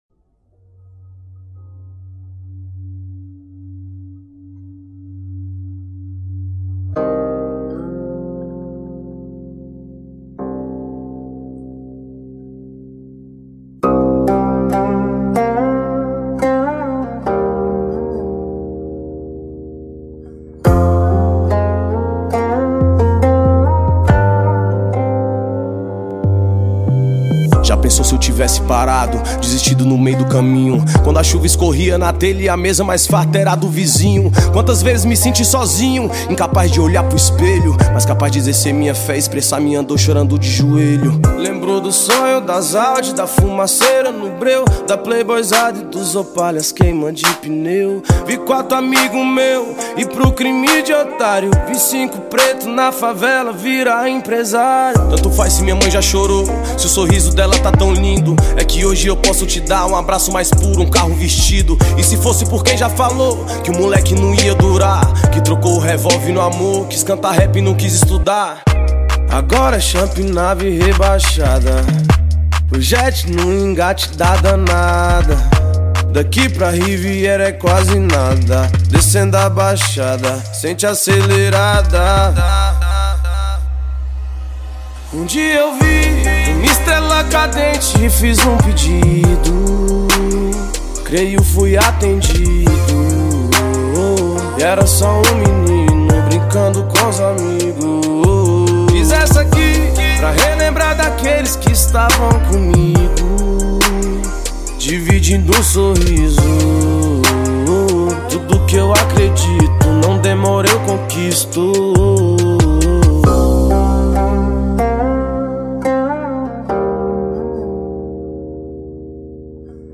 2024-05-10 21:15:52 Gênero: Hip Hop Views